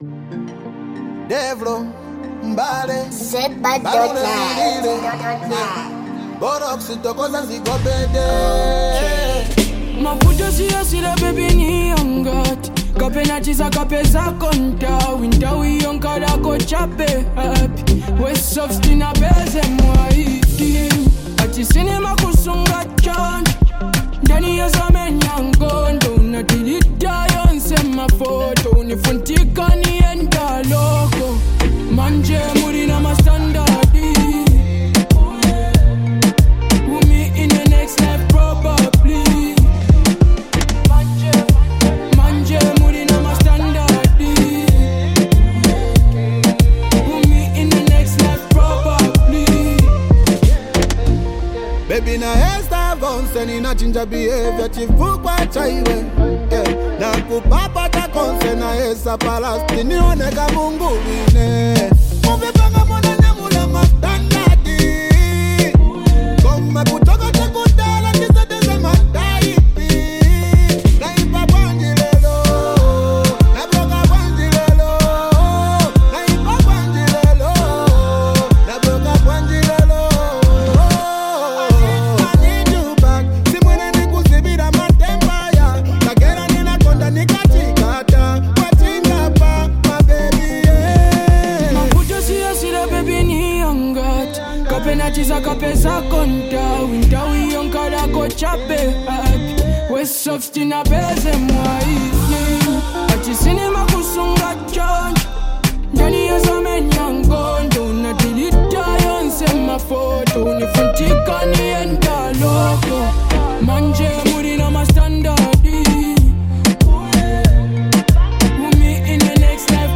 Afrobeats Artist • Lusaka, Zambia